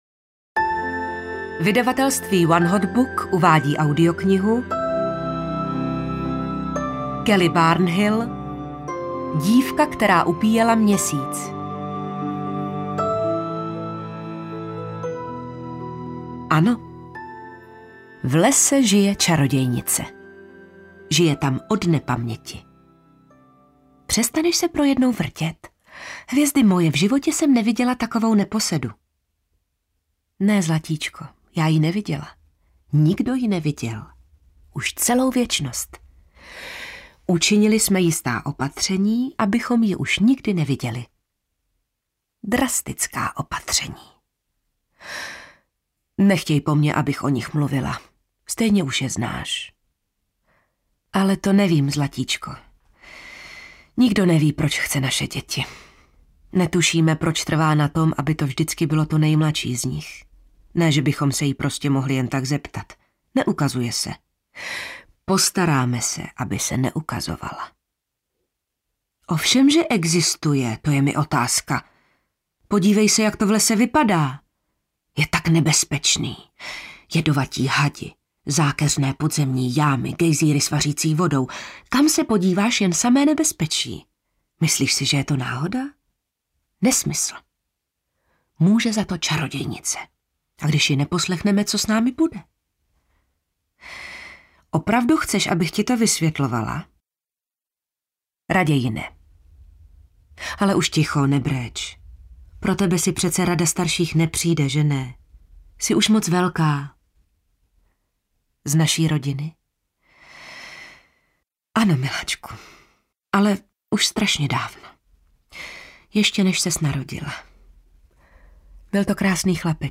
Dívka, která upíjela měsíc audiokniha
Ukázka z knihy